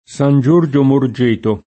San J1rJo morJ%to] (Cal.), Porto San Giorgio [p0rto San J1rJo] (Marche), Castel San Giorgio [kaSt$l San J1rJo] (Camp.)